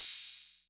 OpenHat1.wav